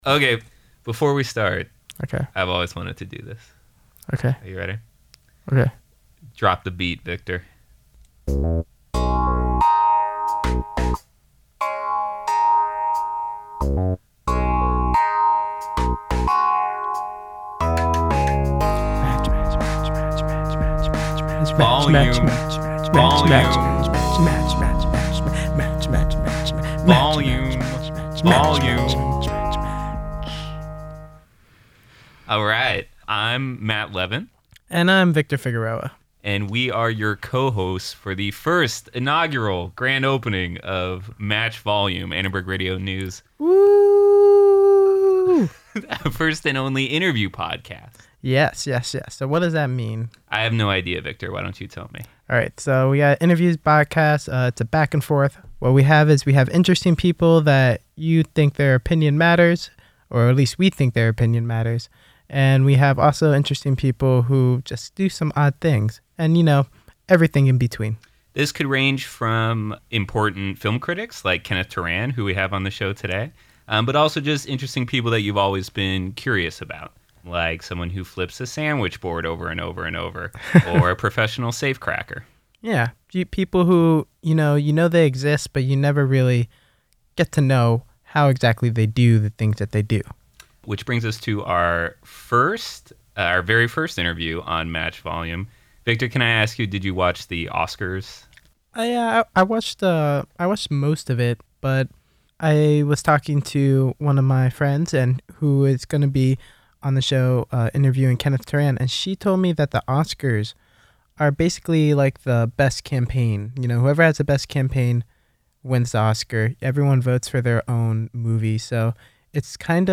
Interviews with Los Angeles Times film critic Kenneth Turan